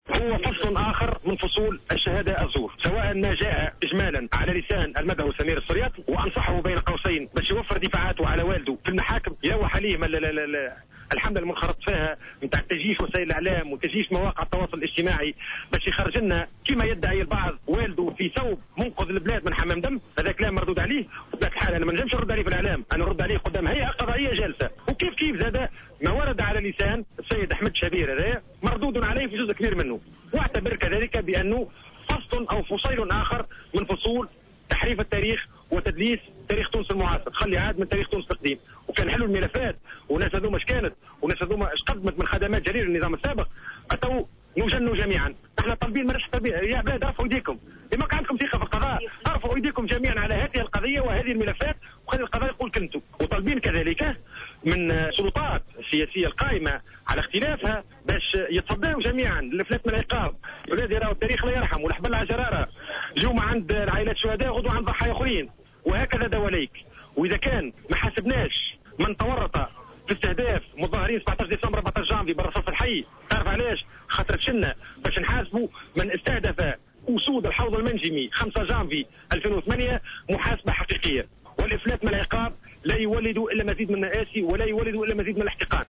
une intervention sur les ondes de Jawahara Fm